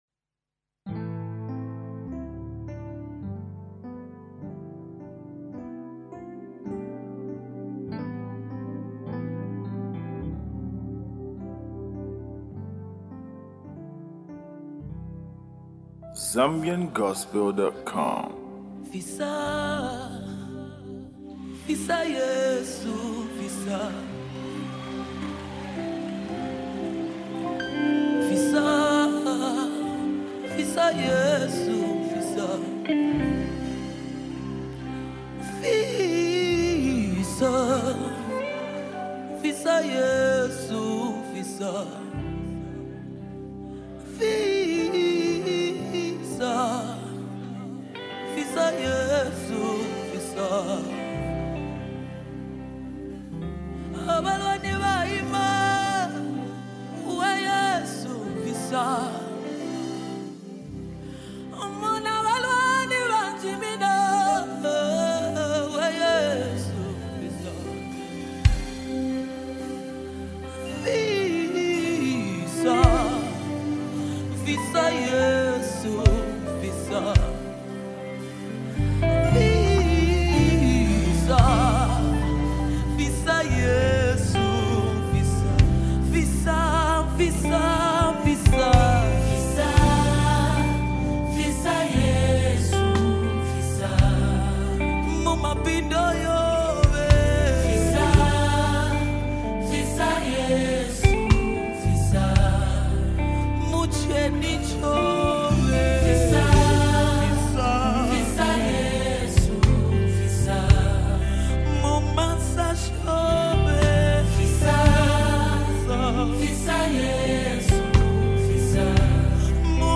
an inspiring gospel song